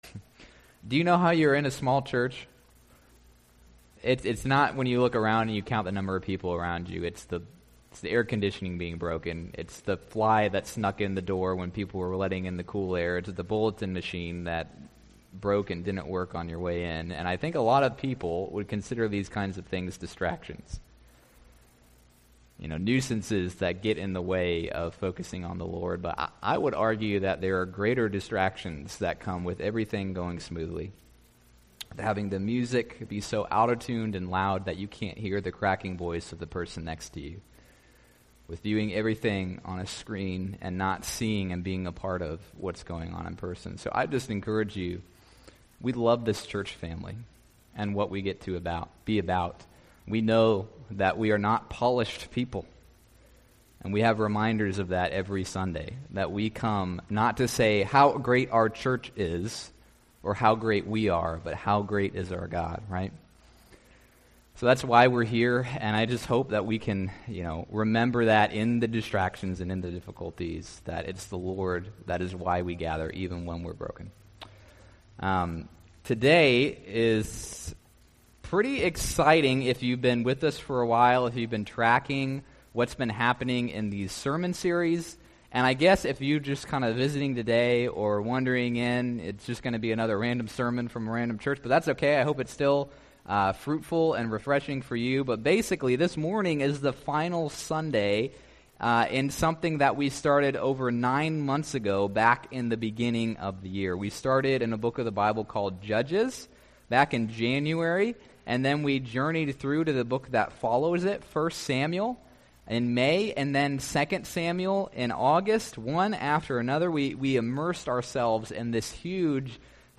Learn more about Eastville Baptist Church on the Eastern Shore of Virginia here on its website, from service times to sermon recordings and more!